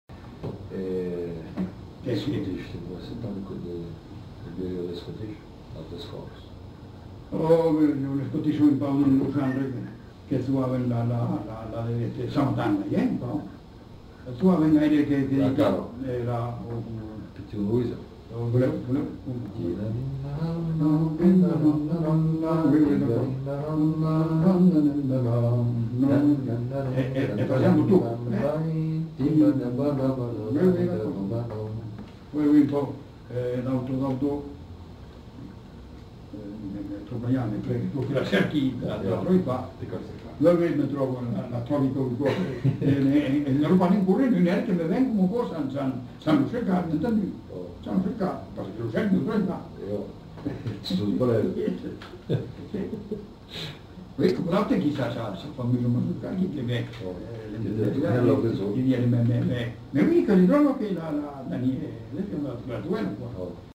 Aire culturelle : Haut-Agenais
Genre : récit de vie